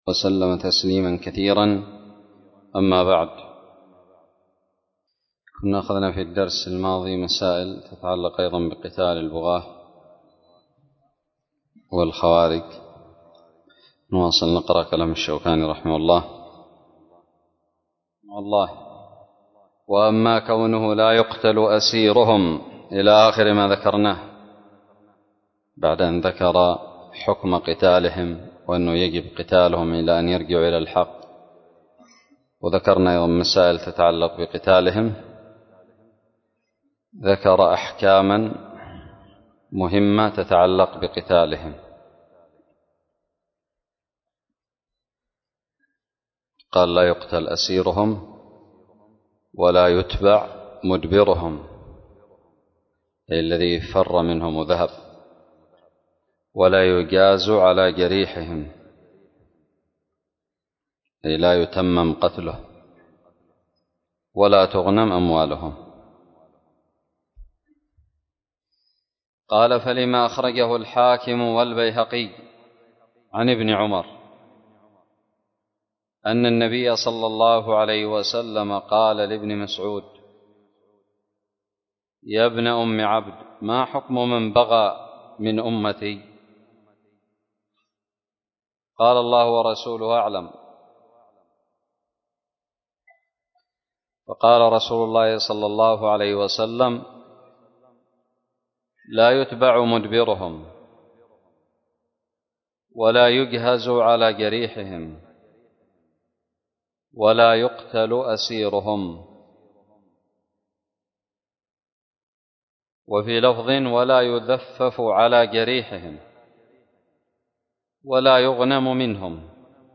الدرس الثاني والخمسون من كتاب الجهاد من الدراري
ألقيت بدار الحديث السلفية للعلوم الشرعية بالضالع